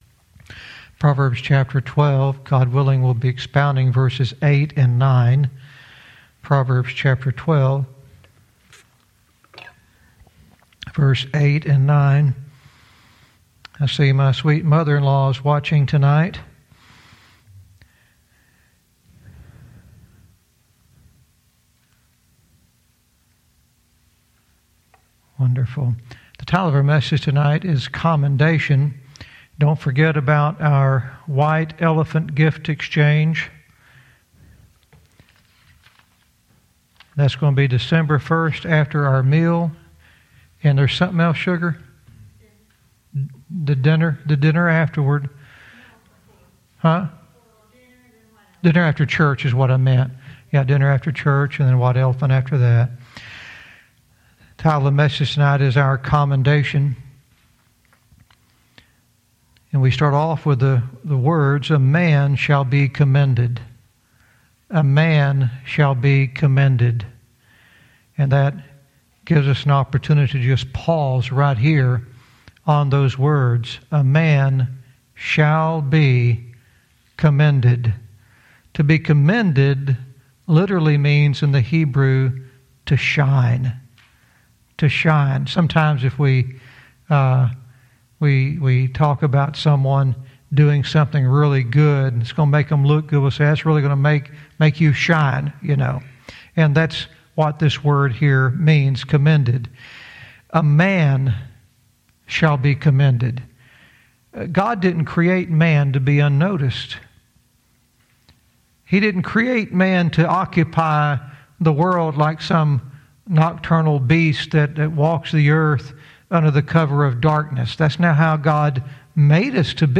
Verse by verse teaching - Proverbs 12:8-9 "Our commendation"